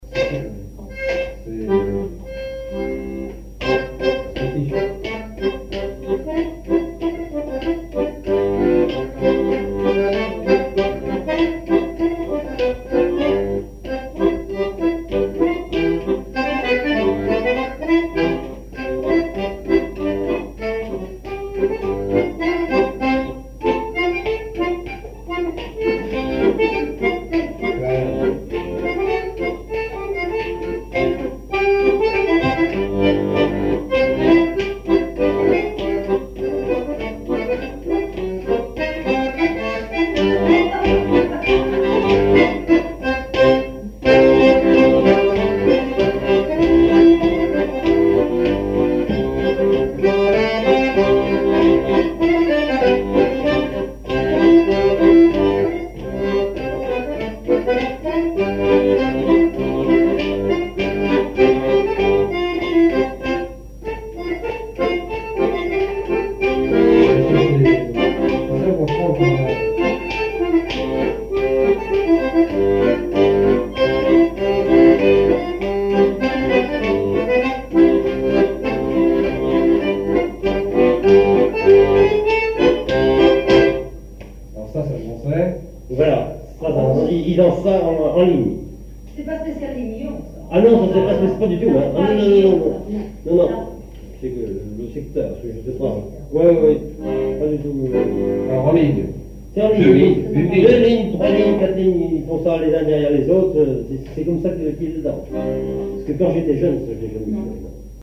danse : spirou
répertoire à l'accordéon diatonique
Pièce musicale inédite